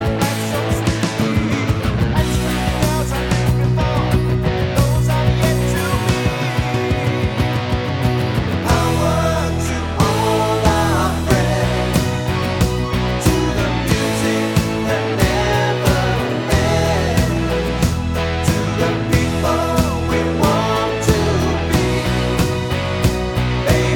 Two Semitones Up Pop (1960s) 2:56 Buy £1.50